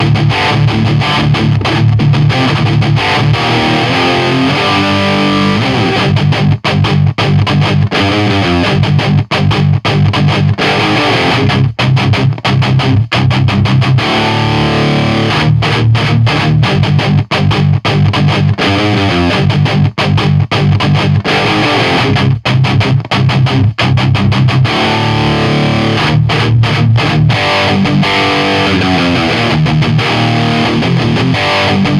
Хай-гейн реампинг - выйдет ли толк?
Вопрос, собственно, простой - можно ли имеющийся чистый звук реампнуть до чего-то, в общих чертах напоминающее референсы?